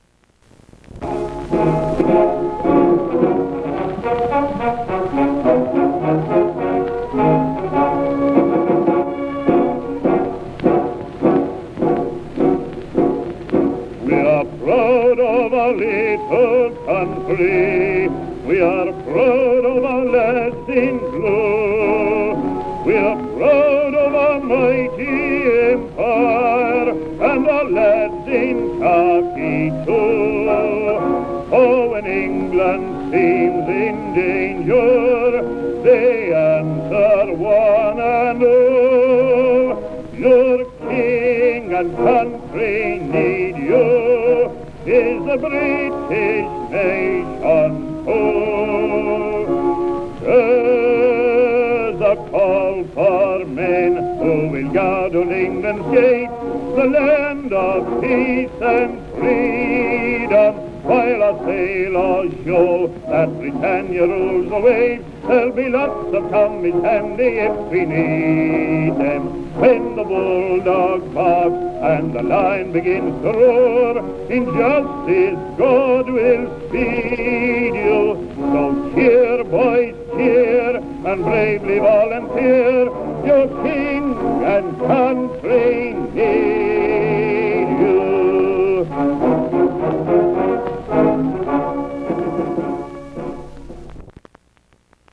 Patriotic music War songs
Originally recorded in 1914.